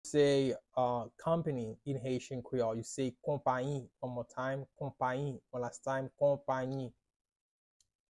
“Company” in Haitian Creole – “Konpayi” pronunciation by a native Haitian tutor
“Konpayi” Pronunciation in Haitian Creole by a native Haitian can be heard in the audio here or in the video below: